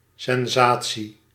Ääntäminen
IPA: [sɑ̃.sa.sjɔ̃]